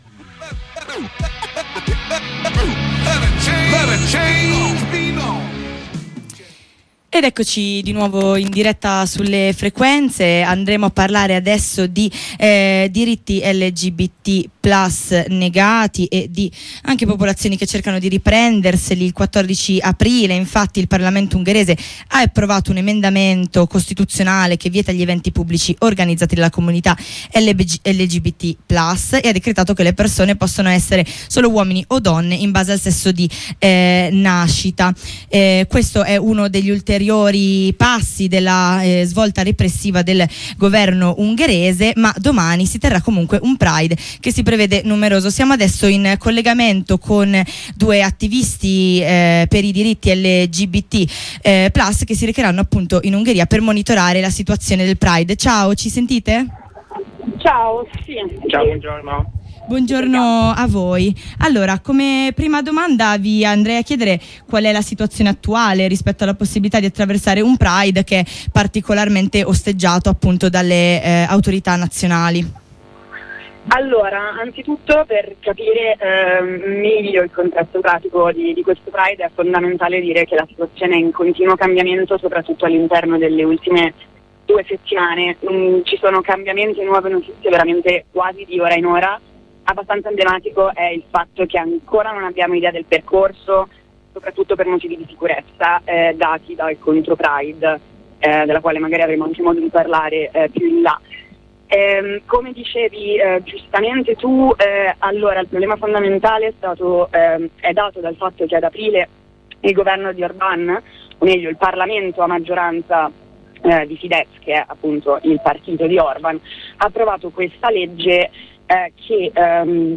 Ne parliamo con due attivisti per i diritti LGBTQIA+, diretti al pride di domani: